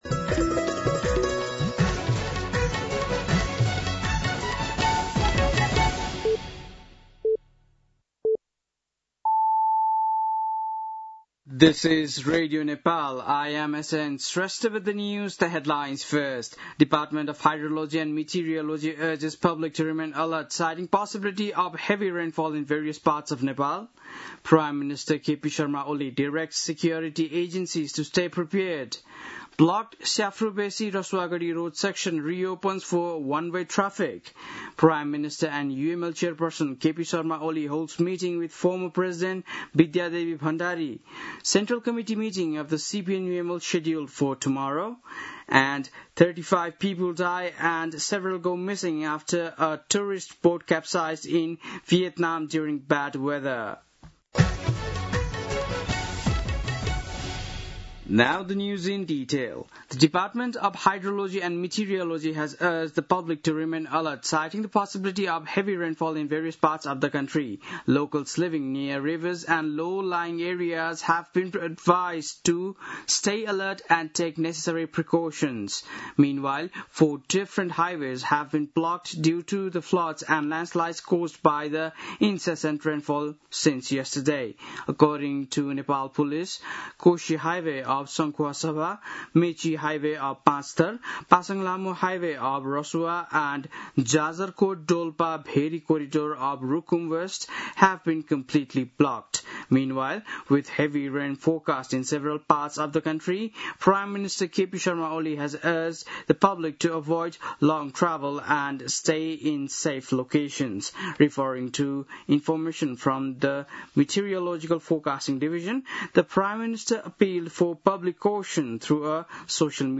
बेलुकी ८ बजेको अङ्ग्रेजी समाचार : ४ साउन , २०८२
8-pm-english-news-4-4.mp3